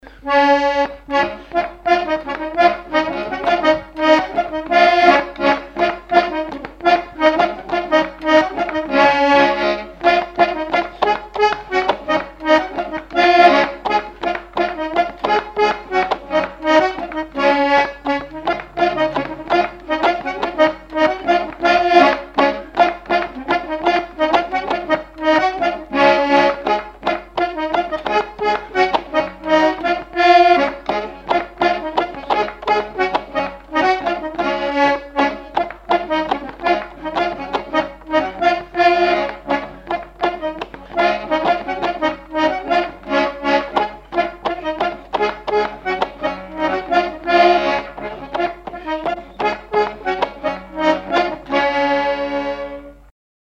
Mazurka
danse : mazurka
Répertoire instrumental à l'accordéon diatonique
Pièce musicale inédite